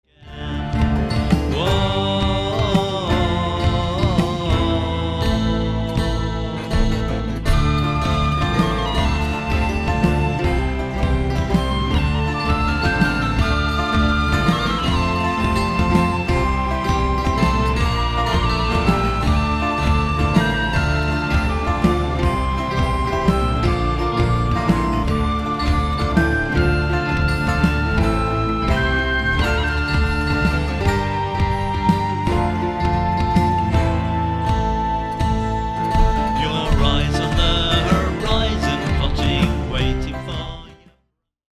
♫  (play the same but with whistles)
Ah. Whistles. That's better.